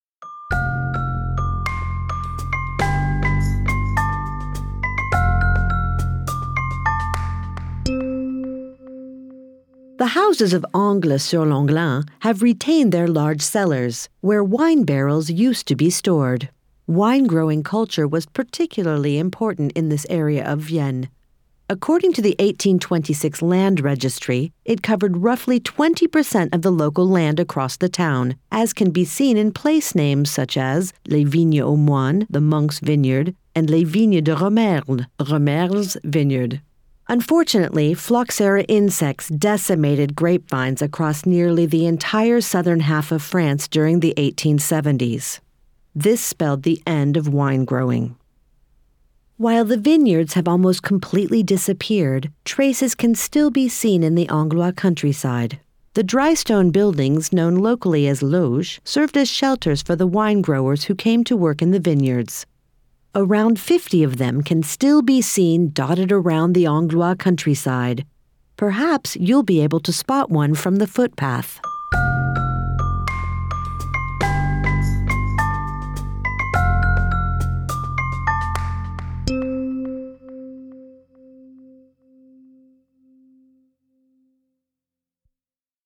Audio guide
Voix off
accent-americain